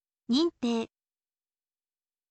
nin tei